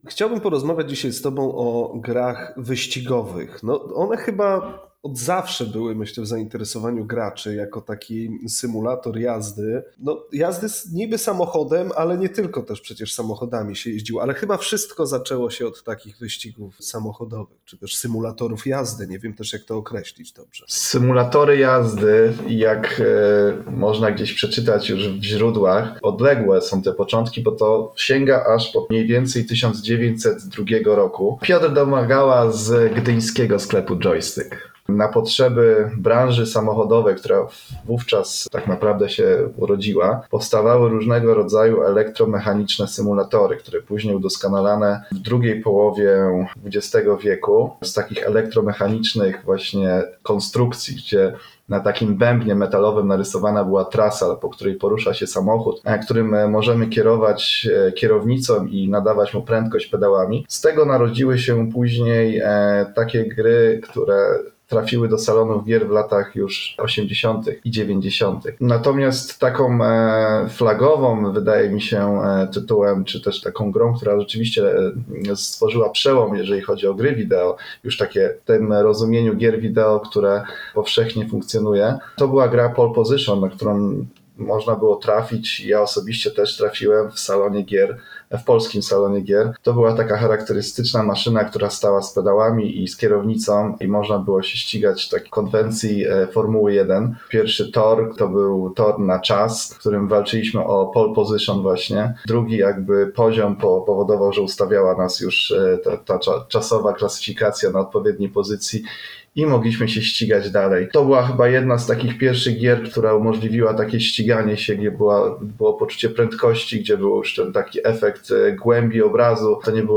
W naszej rozmowie nie zabraknie oczywiście kultowego LOTUSa.